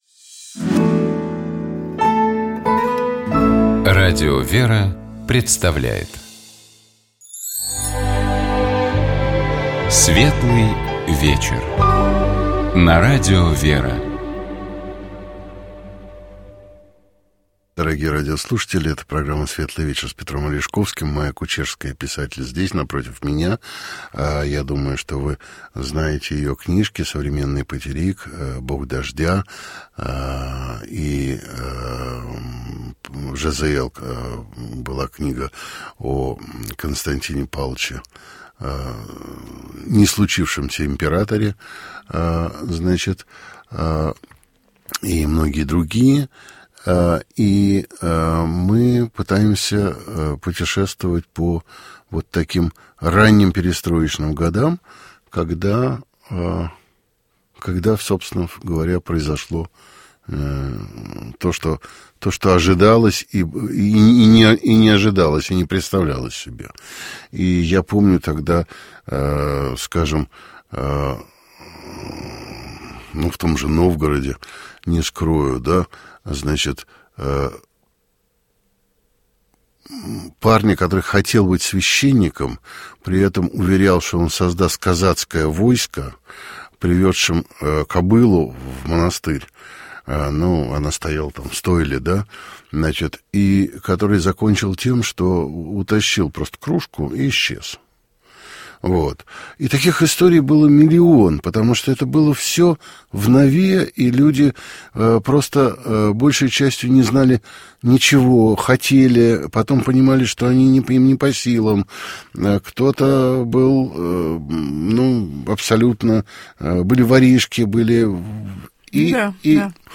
В гостях у Петра Алешковского была писатель, журналист Майя Кучерская.